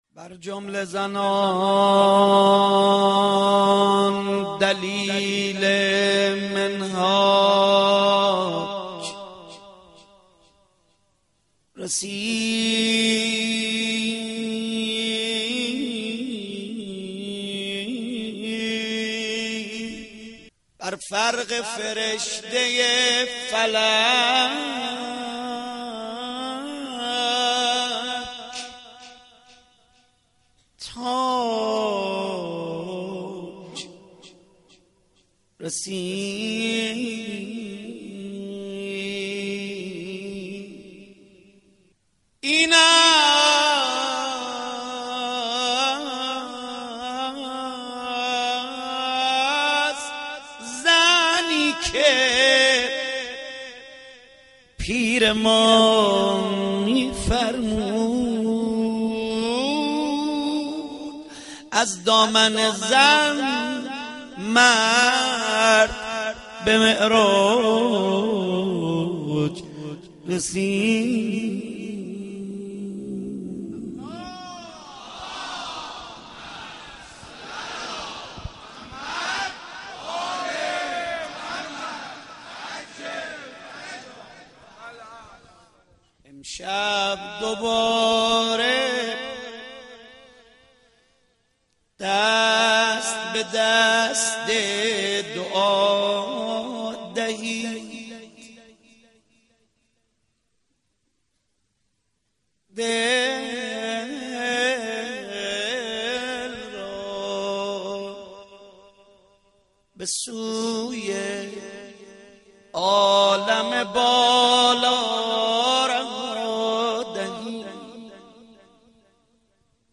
پادکست : تک آهنگ
دسته : پاپ